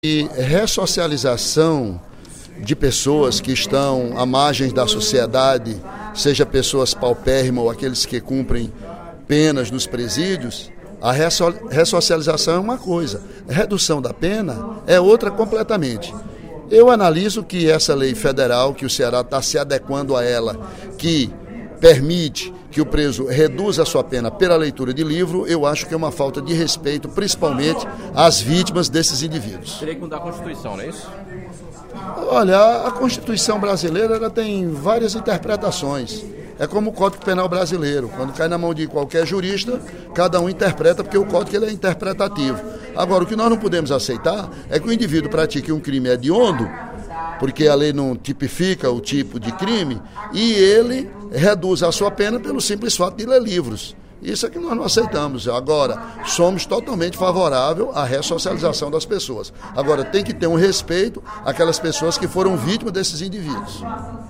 O deputado Ely Aguiar (PSDC) ocupou o primeiro expediente da sessão plenária desta terça-feira (09/12) para criticar a aprovação do projeto de lei que institui o Projeto de Remição pela Leitura no Ceará. De autoria do governador Cid Gomes, a matéria reduz a pena, em quatro dias, do detento que ler um livro por mês.